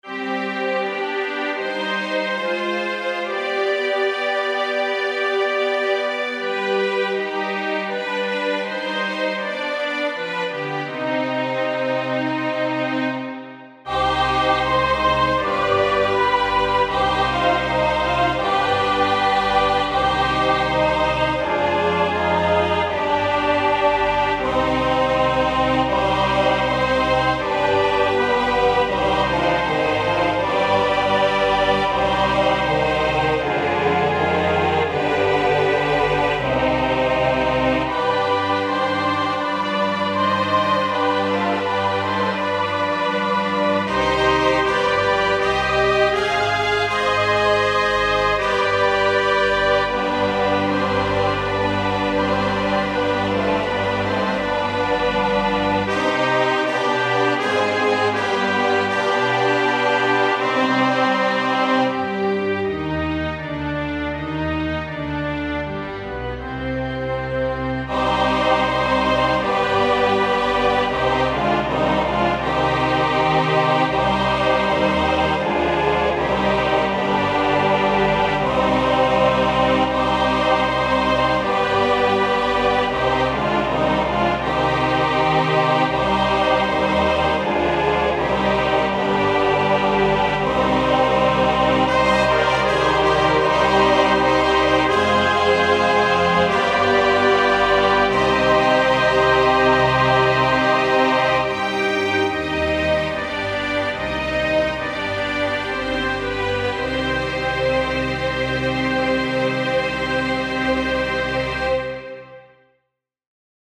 Number of voices: 4vv   Voicing: SATB
Genre: SacredMass
Instruments: Orchestra